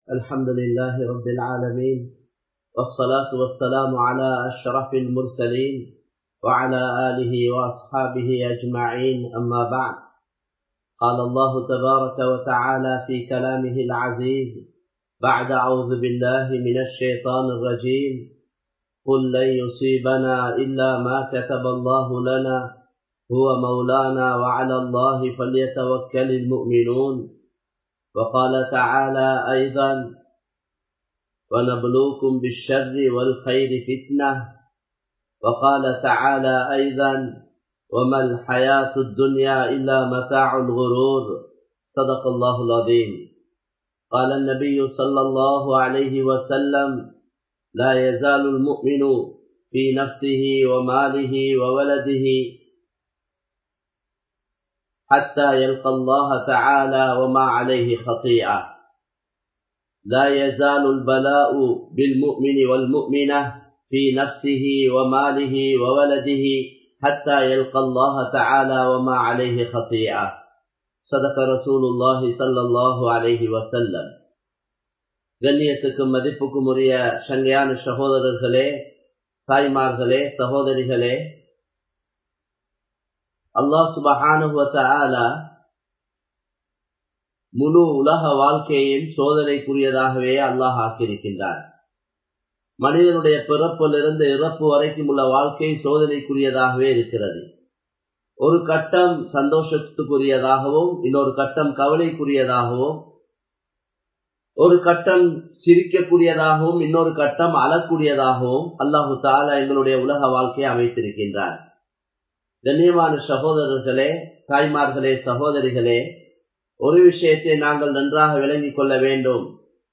Eemanum Muslimum (ஈமானும் முஸ்லிமும்) | Audio Bayans | All Ceylon Muslim Youth Community | Addalaichenai
Live Stream